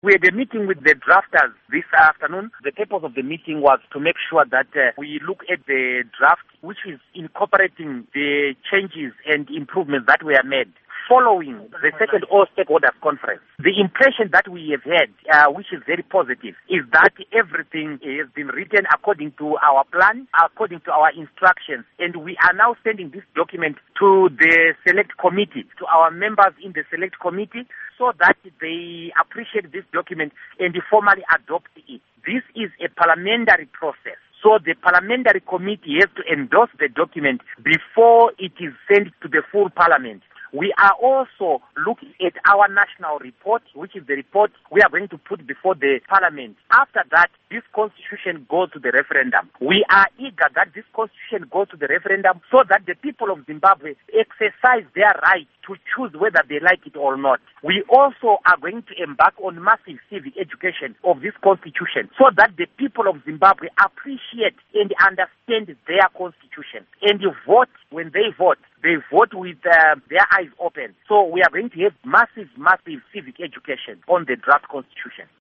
Interview With Douglas Mwonzora